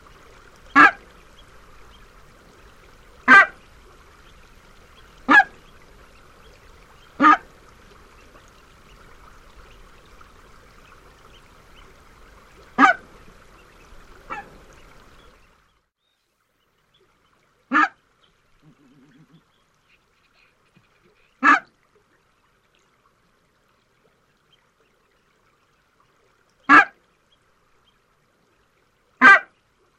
Bernache nonnette - Mes zoazos
bernache-nonnette.mp3